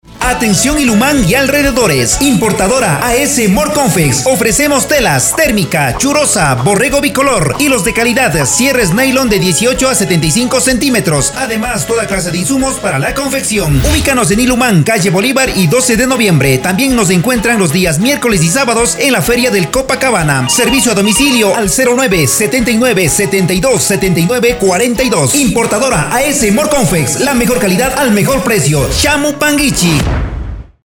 Grabación y Producción de cuñas | Radio Ilumán
En Radio Ilumán te ofrecemos el servicio profesional de grabación de cuñas radiales en dos idiomas: kichwa y castellano, con locutores y locutoras que comunican con autenticidad, claridad y cercanía a la audiencia.